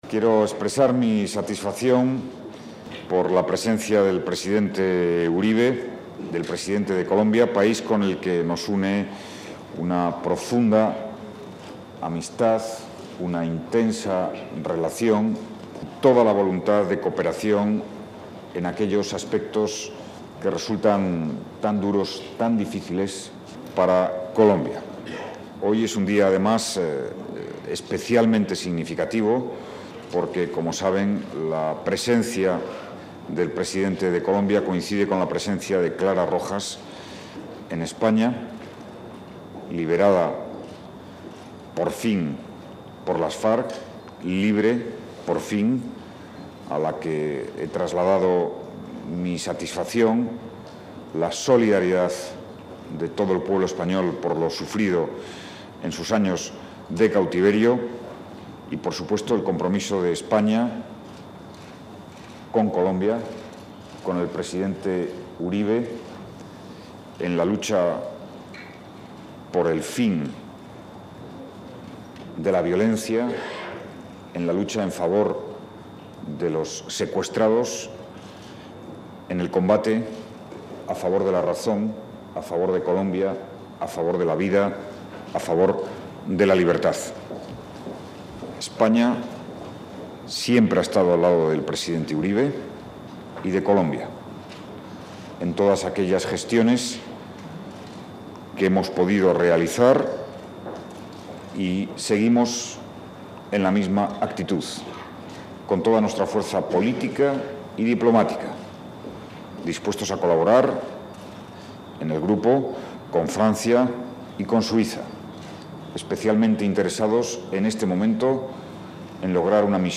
Declaración del Presidente del Gobierno de España, José Luis Rodríguez Zapatero, tras su encuentro con el Presidente Álvaro Uribe